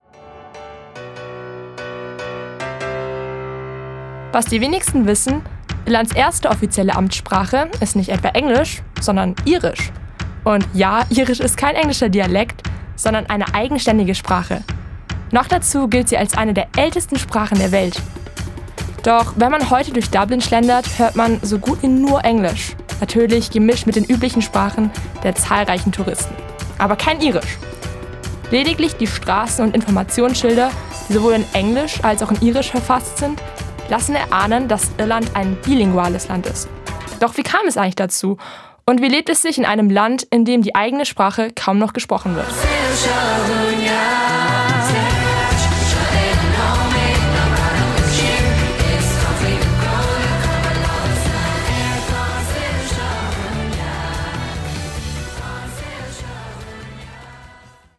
501_Teaser.mp3